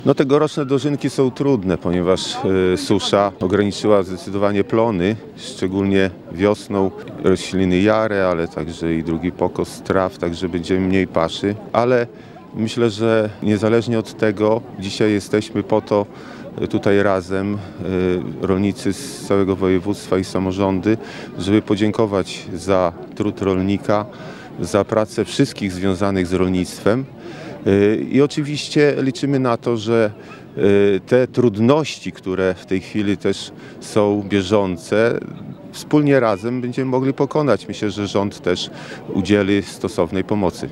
Wojewódzkie dożynki odbyły się dziś w pokamedulskim klasztorze nad Wigrami.
Niezależnie od owoców tegorocznych żniw Jerzy Leszczyński, marszałek województwa podlaskiego, dziękował rolnikom za ich trud i pracę.